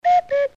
Cuckoo
Tags: botones programa radio fatality la nave